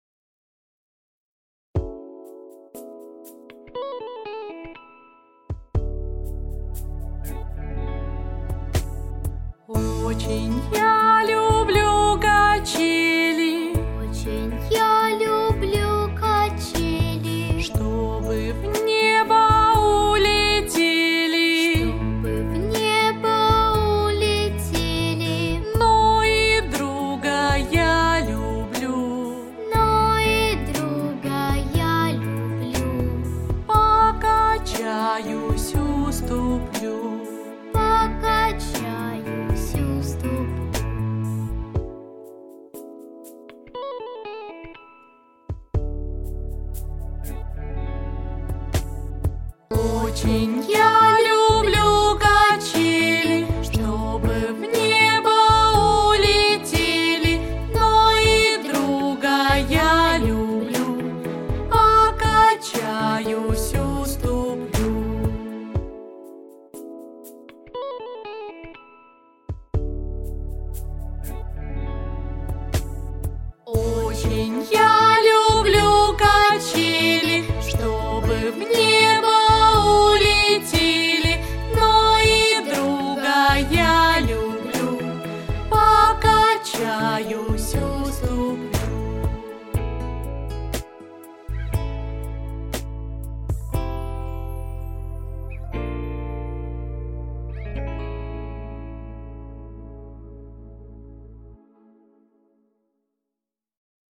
• Категория: Детские песни
распевки